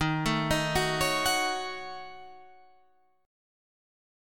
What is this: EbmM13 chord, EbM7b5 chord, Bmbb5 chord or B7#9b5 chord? EbM7b5 chord